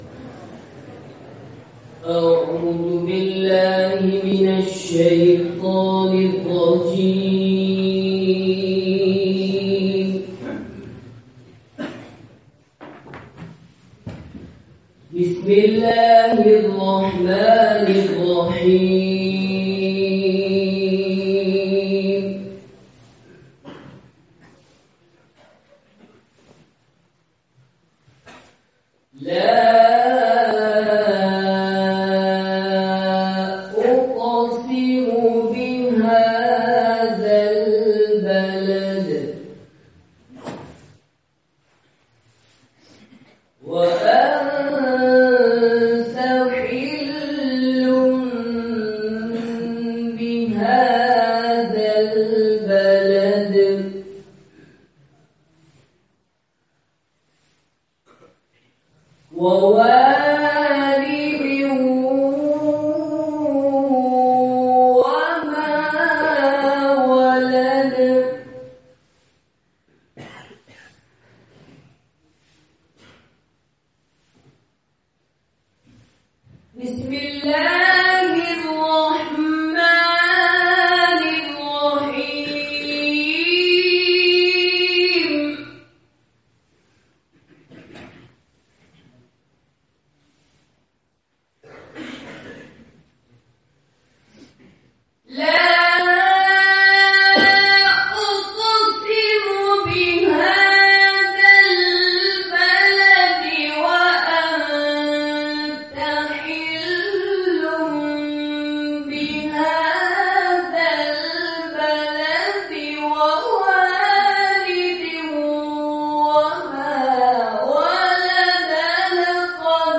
Merey 'Azīz Talabah - 'Ilm kī Qadar Karo (Madrasah Zakariyya, Jogwad, India 23/11/17)